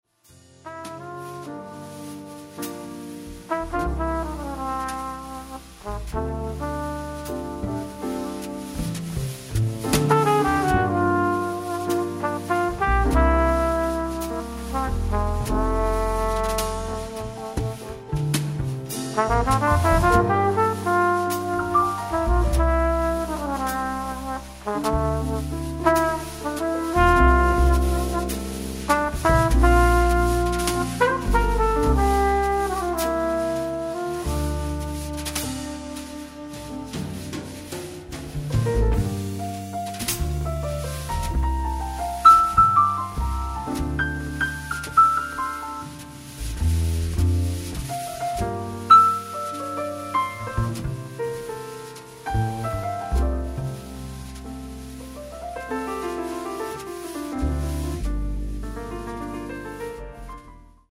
drums
double bass
trumpet/flugelhorn
slide trombone
intriso a più non posso di swing e mainstream